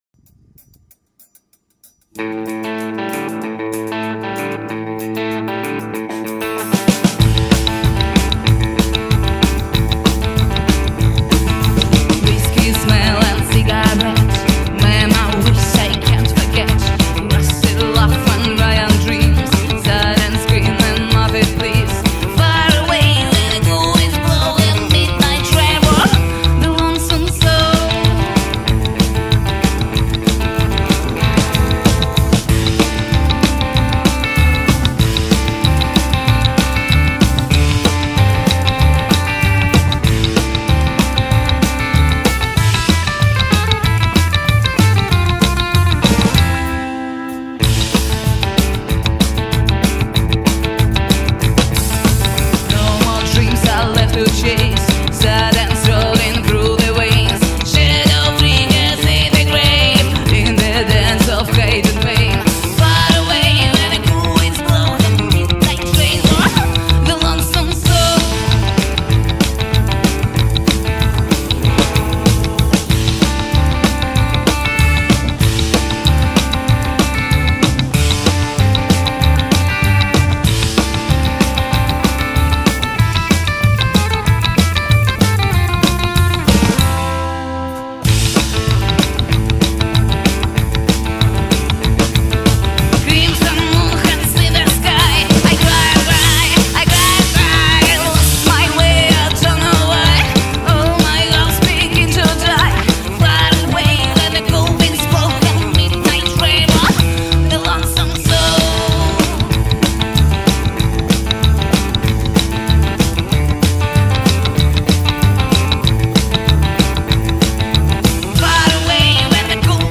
trío de rockabilly
Filmado en vivo Durmitor, Montenegro (2024).
vocals / bass
guitar
drums